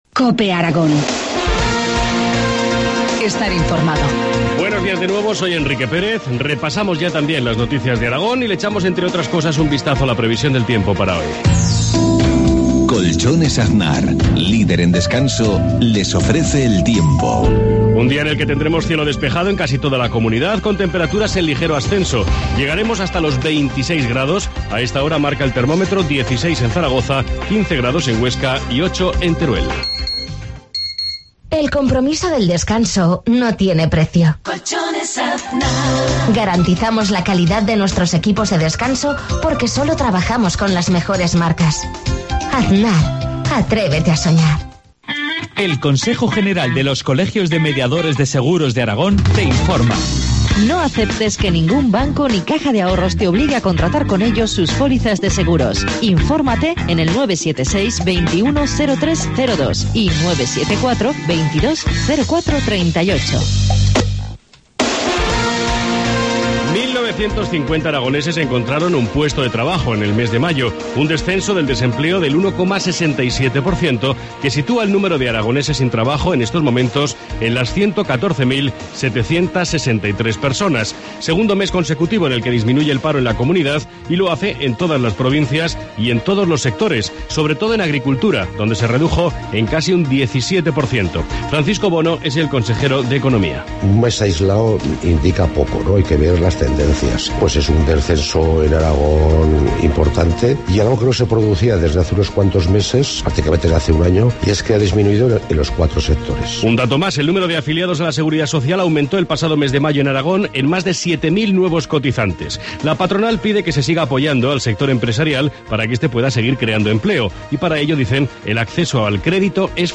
Informativo matinal, miercoles 5 junio 7,53 horas